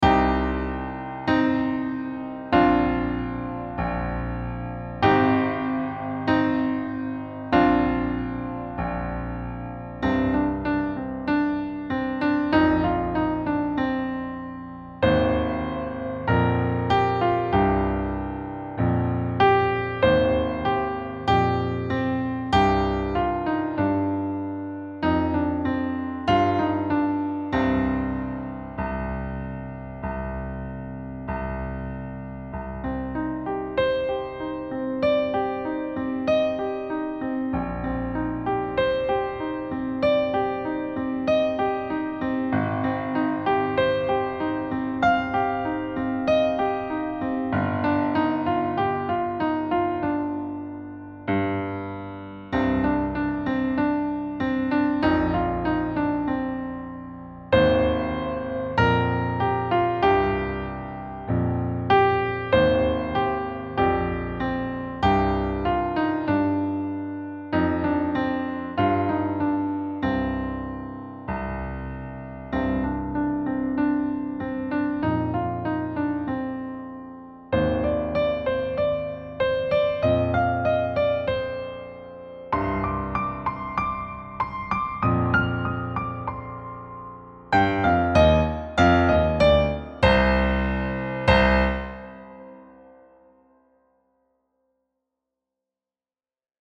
Time Signature: 4/4 (stately march feel)
Level: Late Elementary